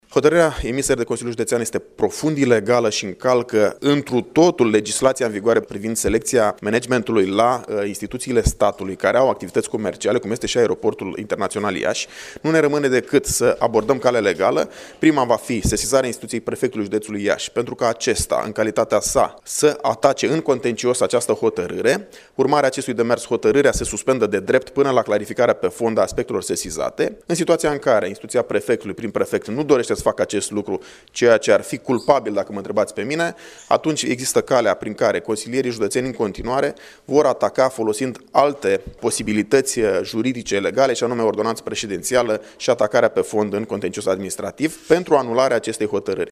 Preşedintele PNL Iaşi, Marius Bodea, a precizat că prima adresă va fi înaintată prefectului, apoi va fi sesizată şi comisia de abuzuri a Camerei Deputaţilor privind situaţia de la Aeroportul Iaşi şi va fi înaintată şi o adresă la Corpul de Control al premierului: